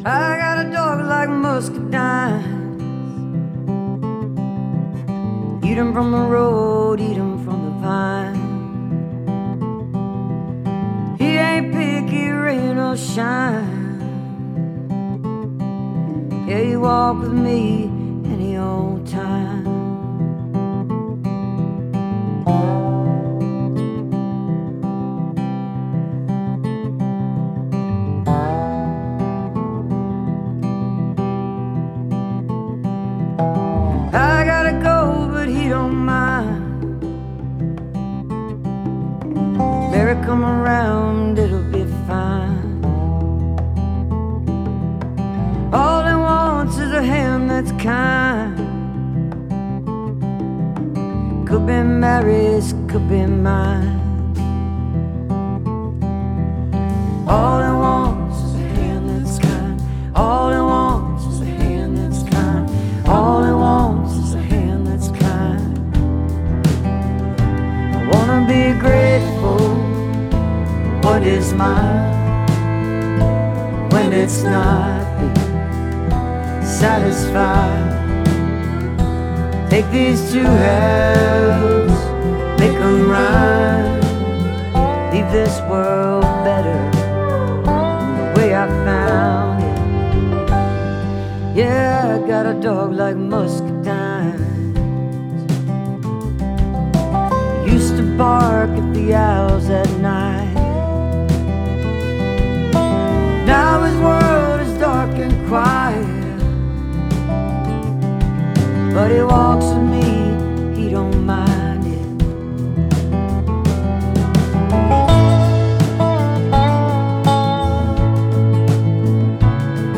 (webstream capture)